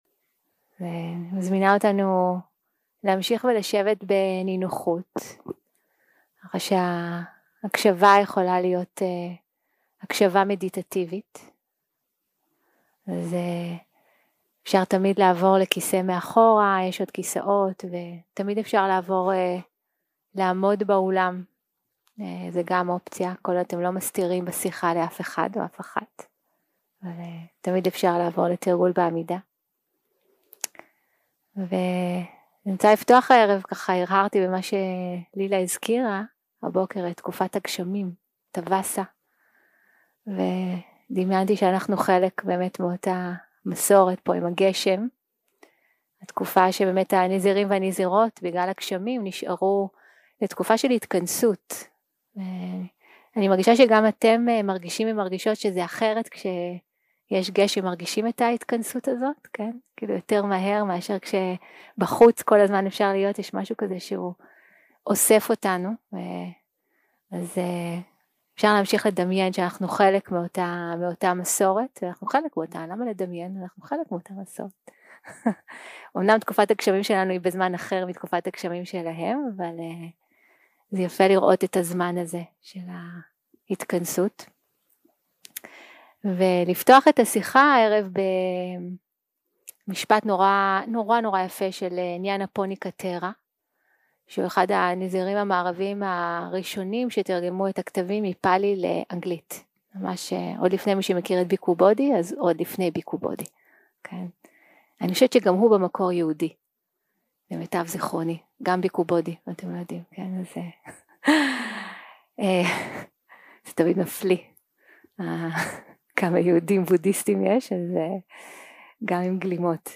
יום 2 - הקלטה 2 - אחהצ - שיחת דהרמה - ללמוד את התודעה, לאמן את התודעה, לשחרר את התודעה 0:00 0:00 סוג ההקלטה: סוג ההקלטה: שיחות דהרמה שפת ההקלטה: שפת ההקלטה: עברית